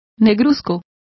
Complete with pronunciation of the translation of dusky.